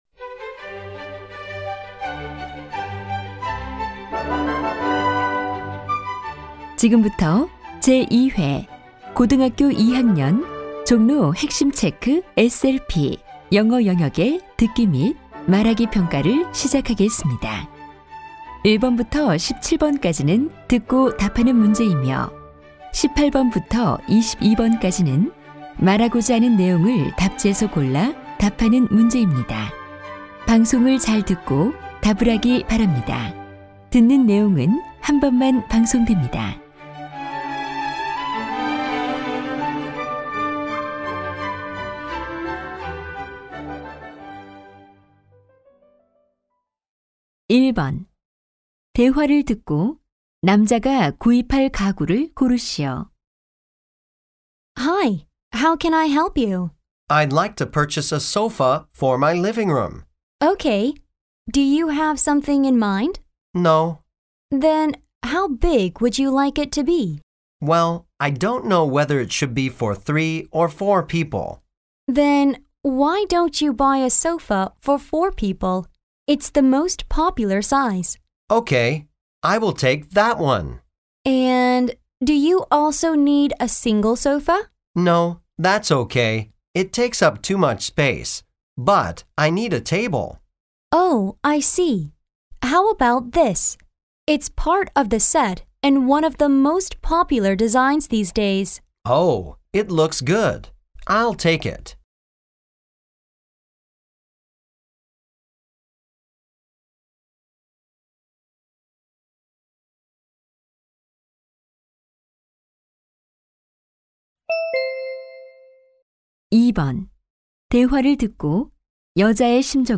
NO 35 제2회 영어듣기모의평가-고2 듣기파일 학습매니저 2012-06-29 조회수 : 6024 제목 없음 안녕하세요.